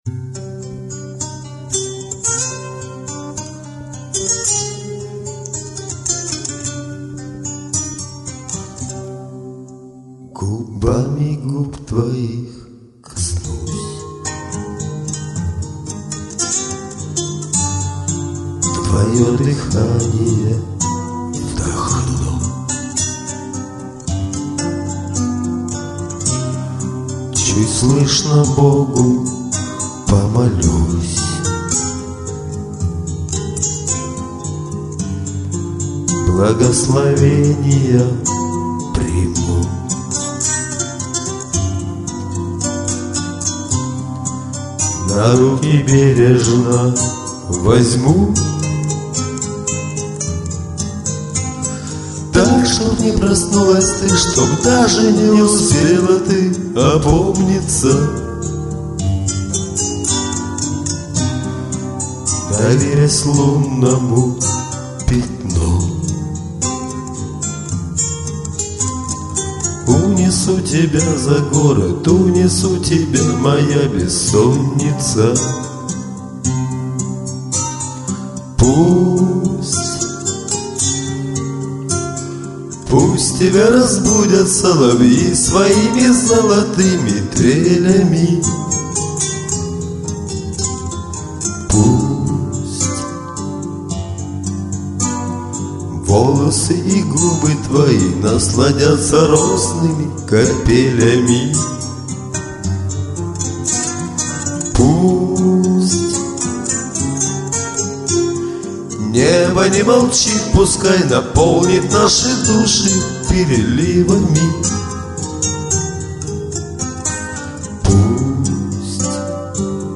Я просто спел 2 раза и получился эффект дуэта!